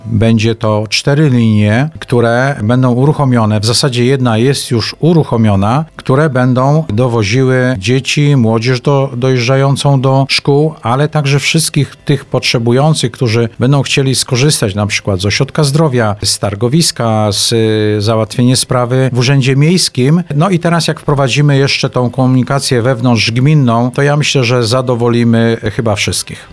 O szczegółach mówi- burmistrz Jedlni Letniska, Piotr Leśnowolski: